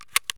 ammo_load7.ogg